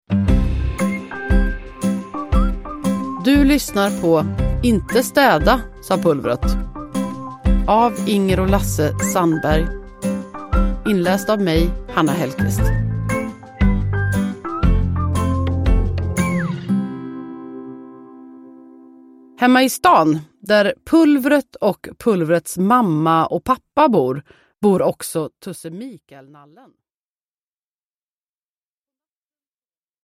Inte städa, sa Pulvret – Ljudbok – Laddas ner
Uppläsare: Hanna Hellquist